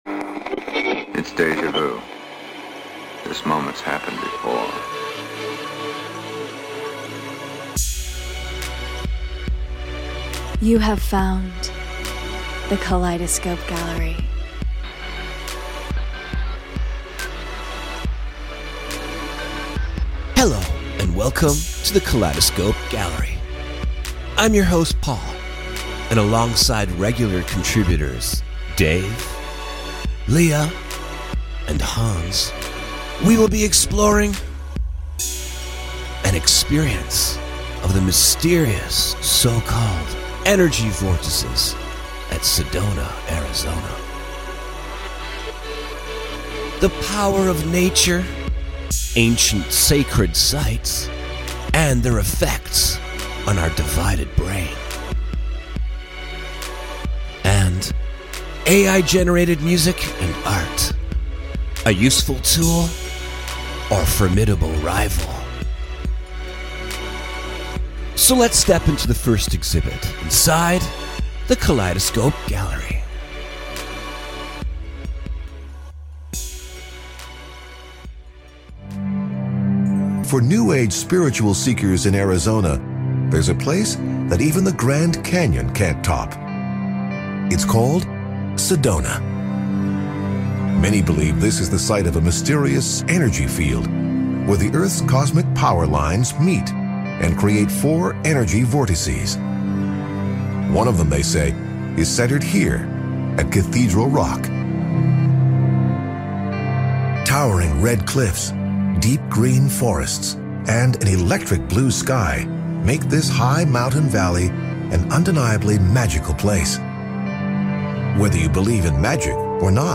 Join us inside the Kaleidoscope Gallery for speculative, irreverent, and enigmatic conversations and stories about the real, the surreal, and somewhere in between.